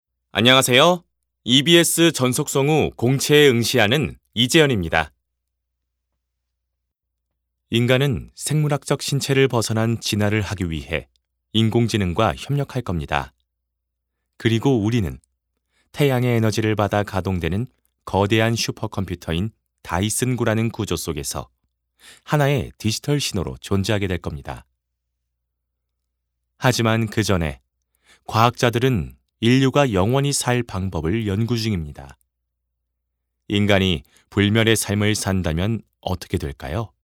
깔끔한 목소리(성우,나레이션)로 작업해드립니다
안녕하세요. 차분하고 진정성있는 목소리입니다.
홍보와 나레이션 위주로 녹음했습니다.